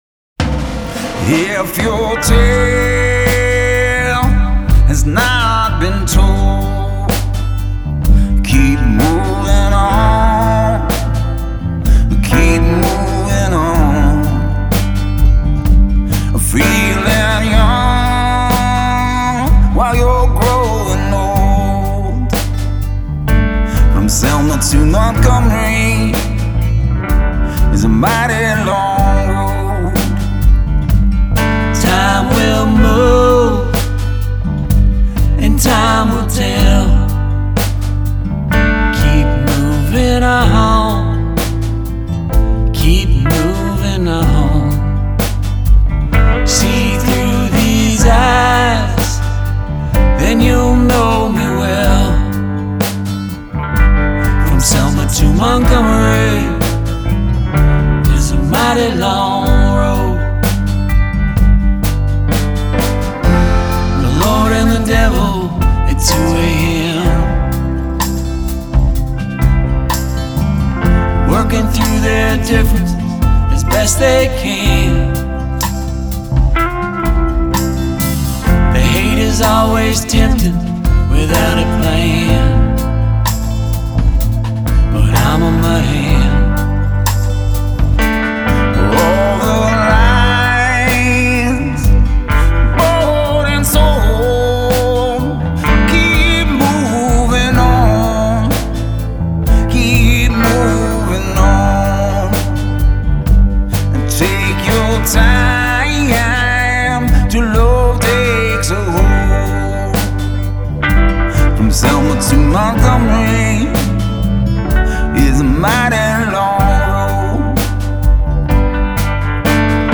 vocals, guitar, piano, drums, bass